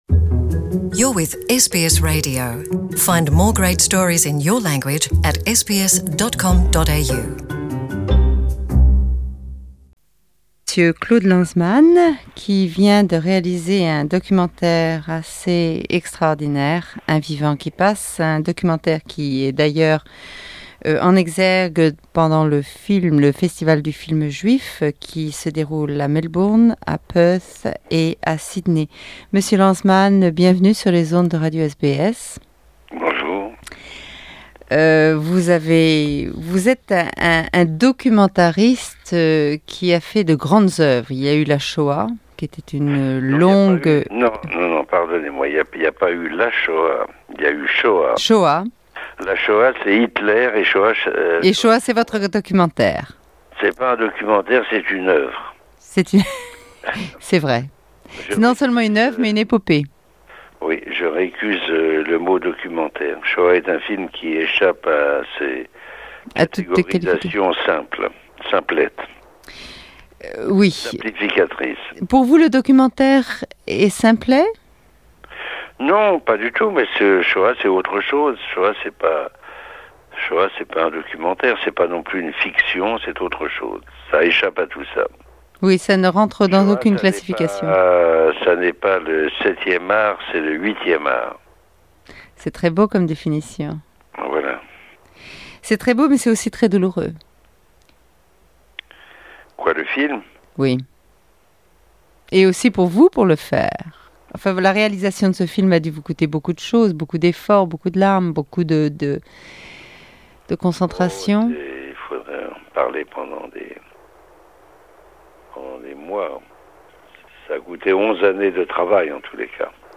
Rediffusion depuis nos archives de l'inteview de Claude Lanzmann